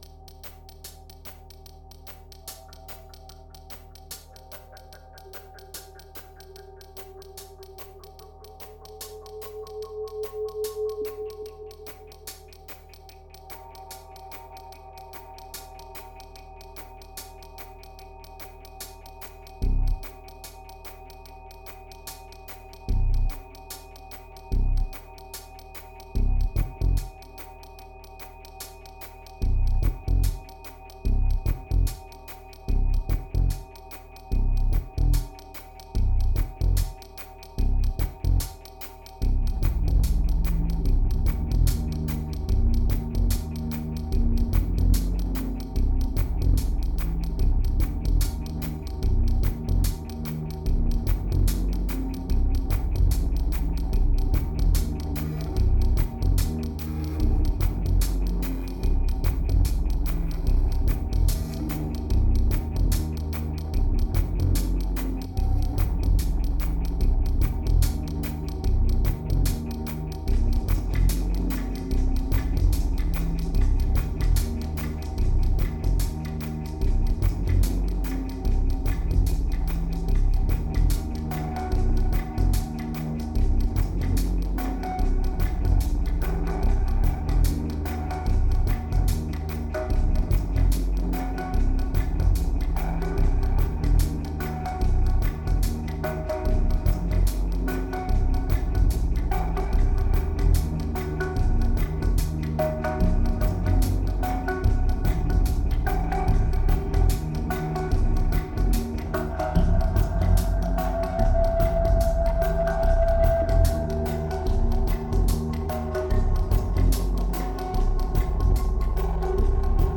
1838📈 - -30%🤔 - 147BPM🔊 - 2010-12-30📅 - -200🌟